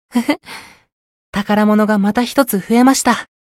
觉醒语音 呵呵。